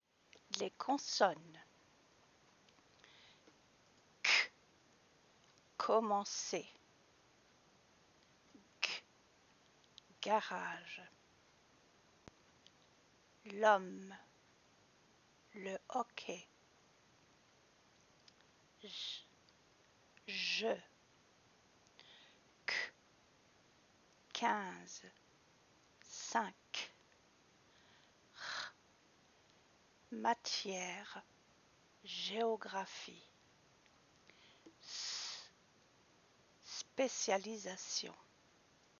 Differences compared to English consonant letters - Les différences comparées aux consonnes anglaises
/k/ – when followed by ‘a’, ‘o’, or ‘u’
/kɔ.mɑ̃.se/
Various versions, depending on region spoken and on place in sentence. Most commonly guttural or trilled (‘rolled’)